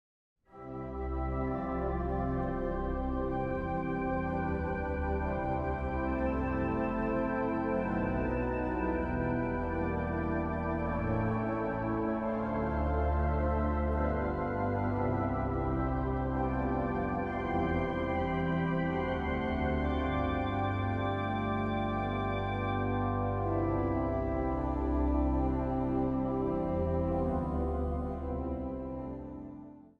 orgel
piano
viool
dwarsfluit
bariton.
Zang | Mannenkoor